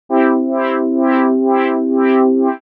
●LFO（サイン波）でカットオフを変化させたパッド音色のサンプル